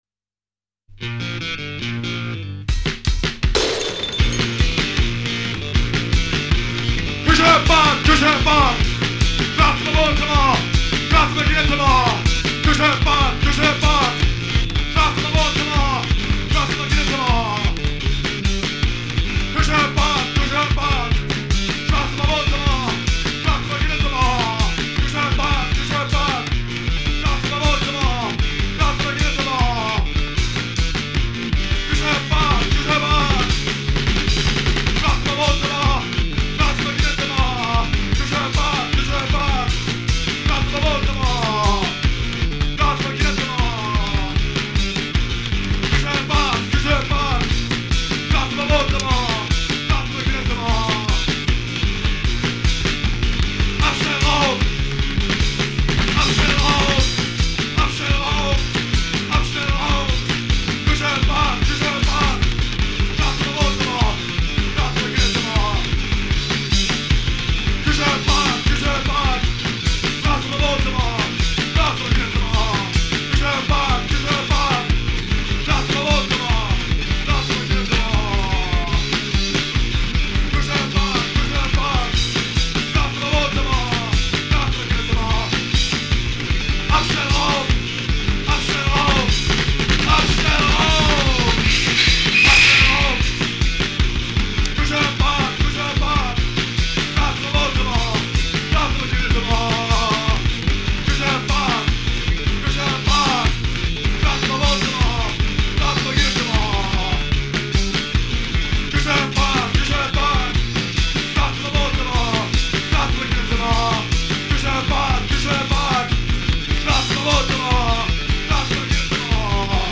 der die Energie von Punk und Rock´n Roll aufschaufelte
Live Im Haus Raststatt
Casette 1981 aufgenommen